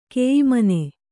♪ keyimane